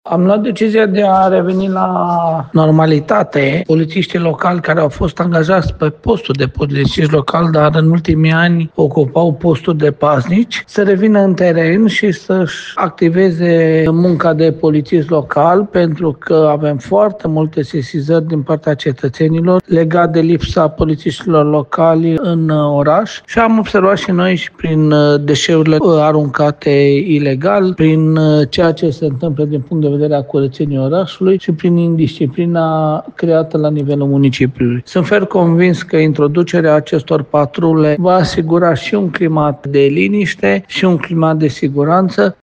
Primarul municipiului Lugoj a mai subliniat că prezența polițiștilor locali în comunitate este esențială pentru un oraș curat și sigur pentru toți locuitorii.
Calin-Dobra-politisti.mp3